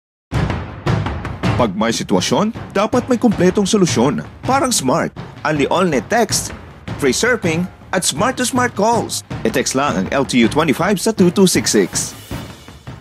SMART UnliAllNet - Radio Commercial RADIO VO